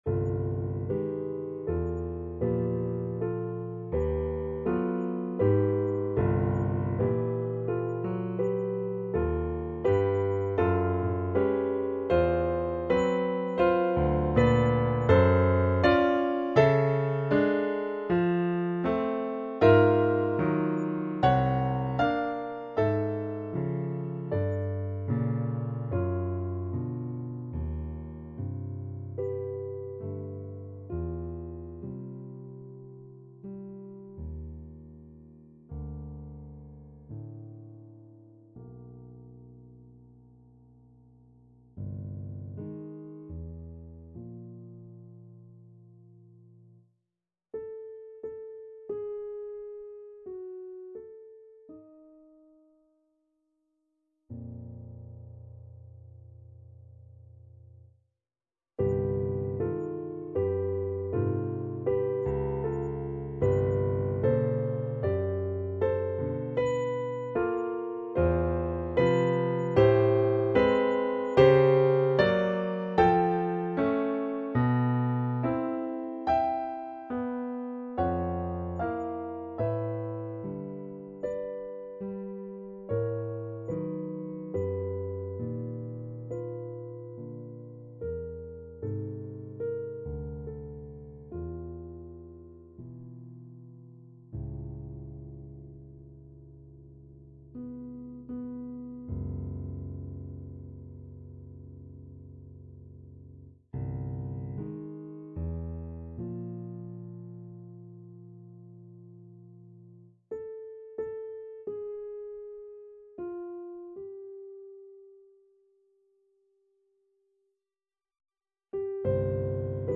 Instrument(s): piano solo.